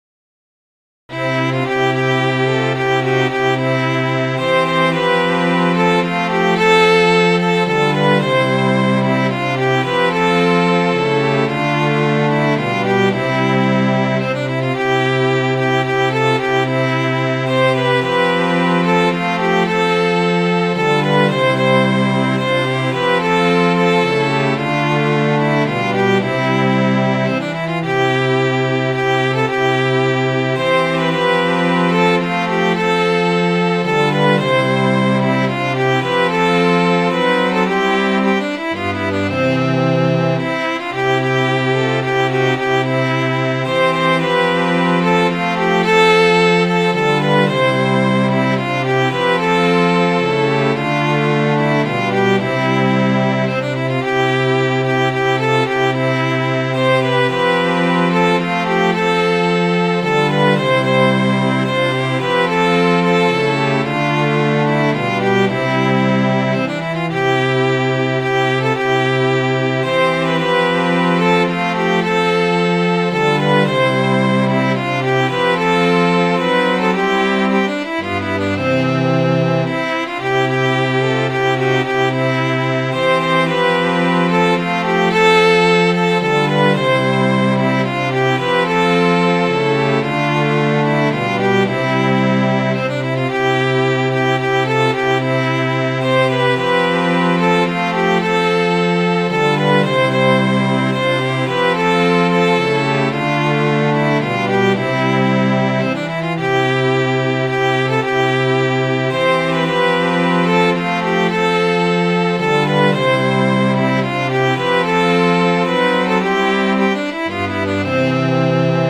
Midi File, Lyrics and Information to Rolling Home